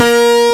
PIASYN.WAV